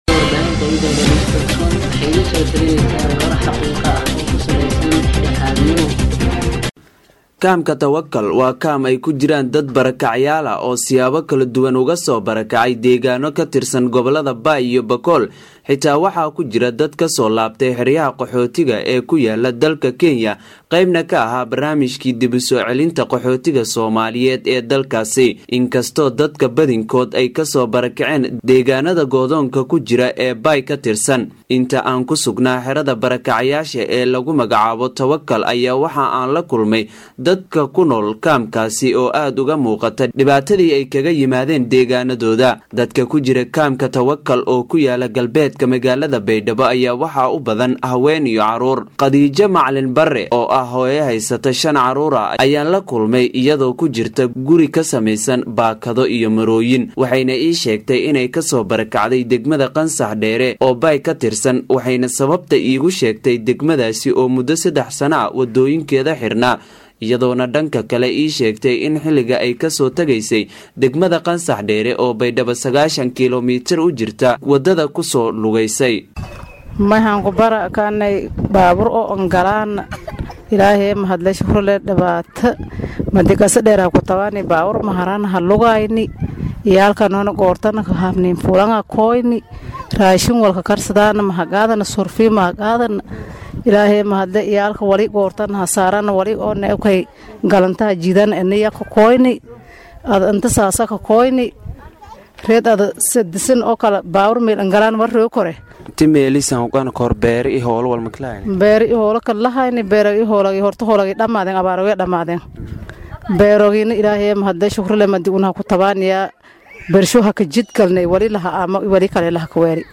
Waxay Idale News Online  la kulantay dad  u badan haween Iyo Caruur da’yar oo  La daala dhacayo Soo saarista Noolal Maalimaadkooda, iyadoo dadkan ay maalin walba burburiyaan Dhagxaata Jaayga ah ee Laga dhiso Guryahaha, isla markaaana waxay sheegeen in Naftoodu ay ku maareynayaan.